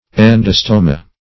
Search Result for " endostoma" : The Collaborative International Dictionary of English v.0.48: Endostoma \En*dos"to*ma\, n. [NL., fr. Gr.